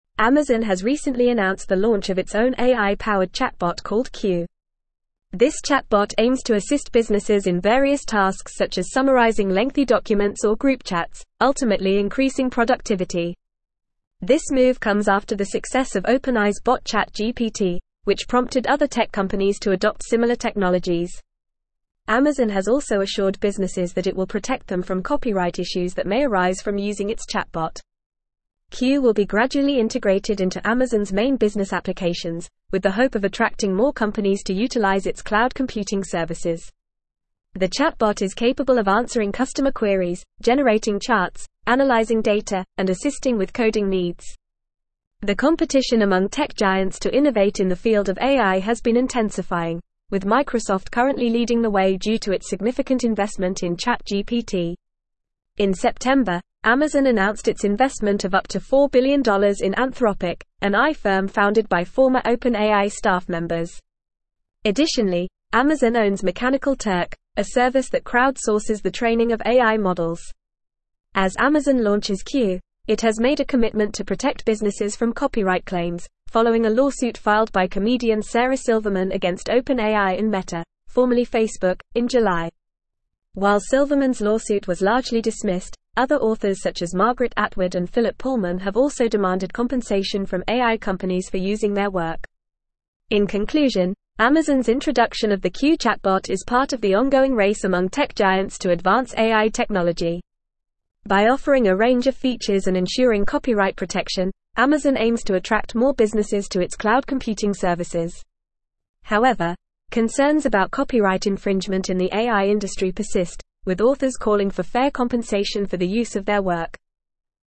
Fast
English-Newsroom-Advanced-FAST-Reading-Amazon-Introduces-Q-Chatbot-to-Boost-Business-Productivity.mp3